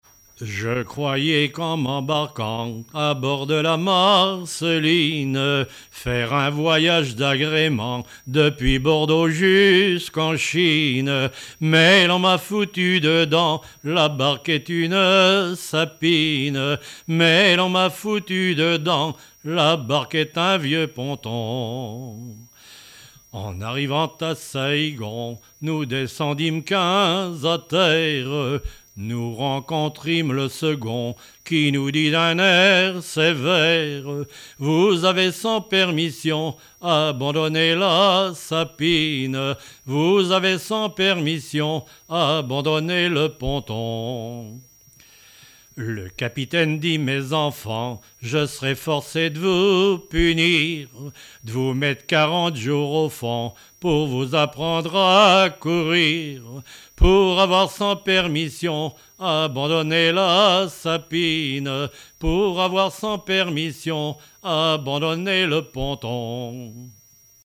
Mémoires et Patrimoines vivants - RaddO est une base de données d'archives iconographiques et sonores.
Chansons traditionnelles et populaires
Pièce musicale inédite